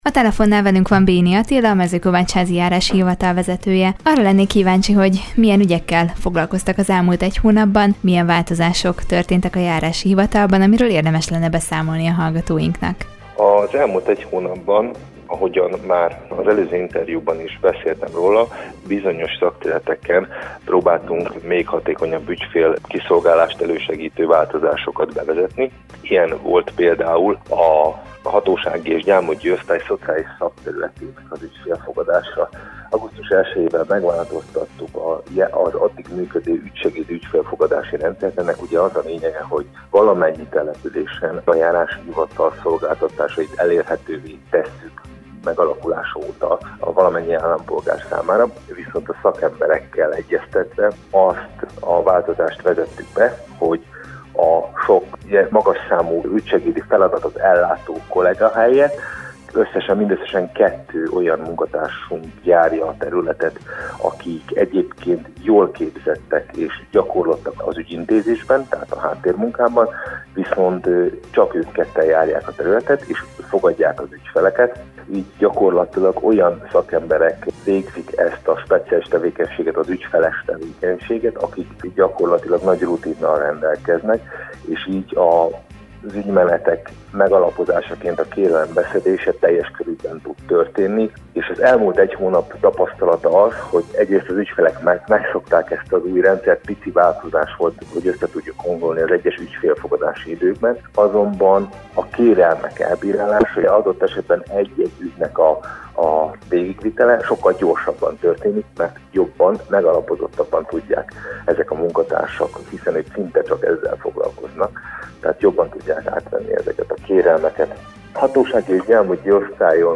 Az elmúlt egy hónapban ahogyan már az előző interjúban is beszéltem róla, bizonyos szakterületeken próbáltunk még hatékonyabb ügyfél kiszolgálást elősegítő változásokat bevezetni – mondta Béni Attila, a Mezőkovácsházi Járási Hivatal vezetője.